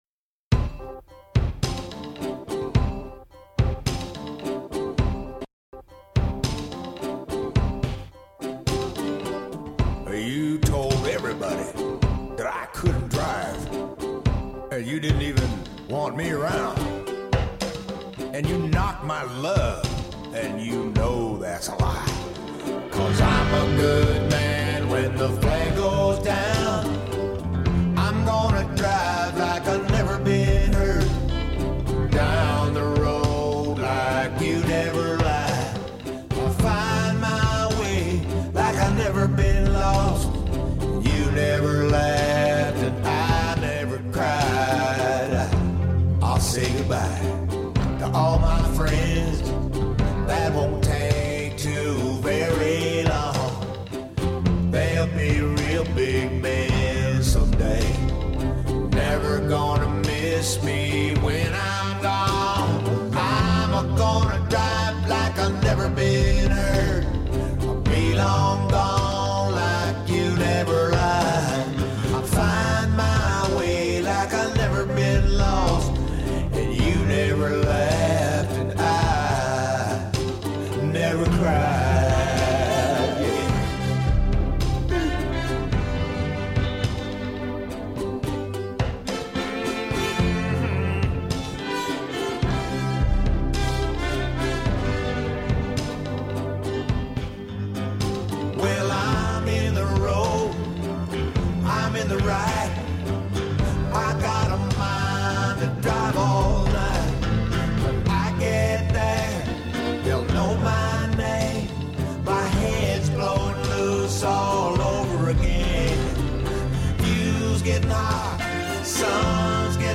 Interview with Ry Cooder (I, Flathead)
01 Interview with Ry Cooder (_I, Flathead_).mp3